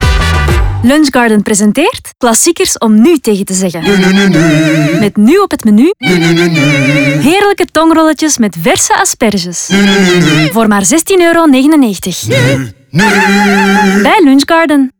radiospotjes